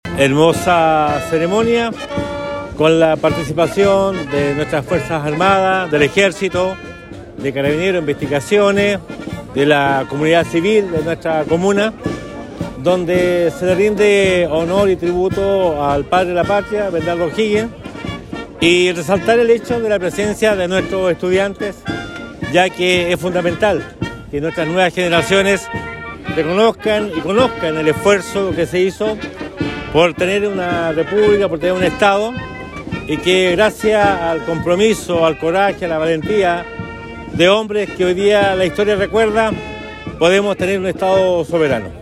Con un acto realizado en Plaza de Armas, que consideró la colocación de ofrendas, Osorno conmemoró el natalicio número 245 del Libertador Bernardo O’Higgins Riquelme, que ocupa un sitial importante en nuestra historia, al participar en la gesta emancipadora para la independencia de Chile.
En la ocasión el Alcalde Emeterio Carrillo destacó la importancia de recordar la figura de Bernardo O’Higgins, siendo aquello clave para las nuevas generaciones, en torno a conocer y valorar el coraje, valentía y amor por la patria de nuestro libertador, que jugó un rol trascendental para un país soberano.